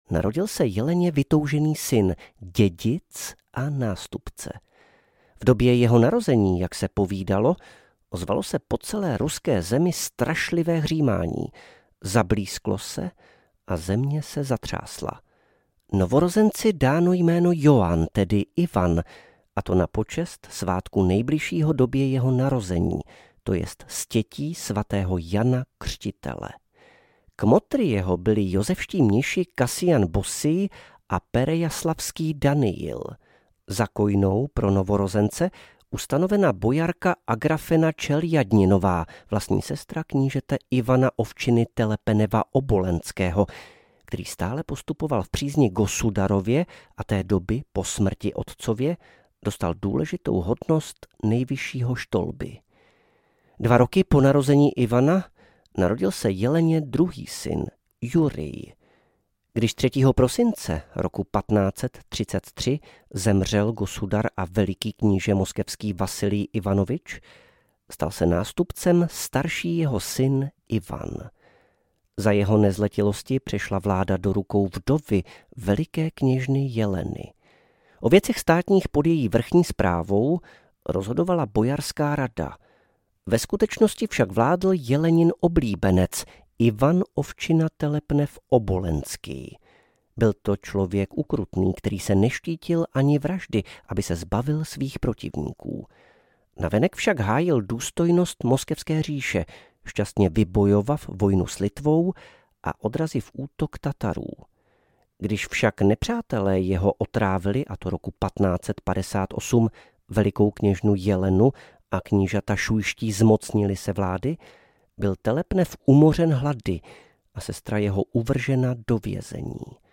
Ivan IV. Hrozný audiokniha
Ukázka z knihy